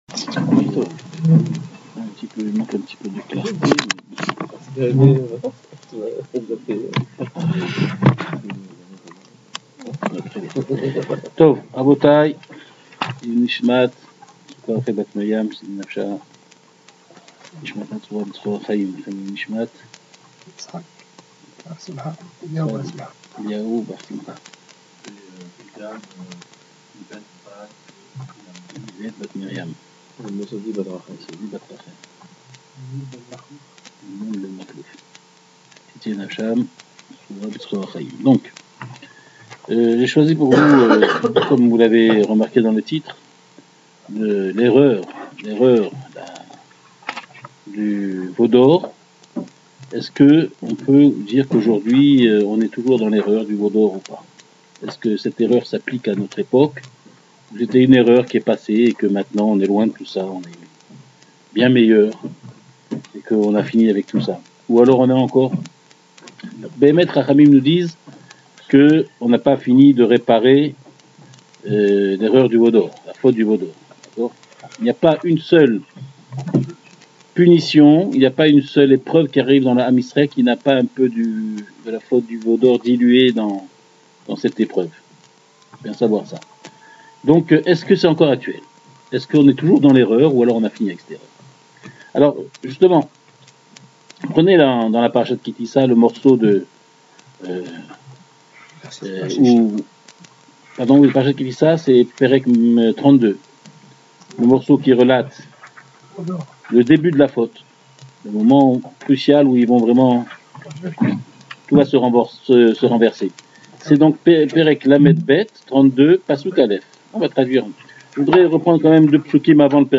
Le cours est donné tous les jeudis soir.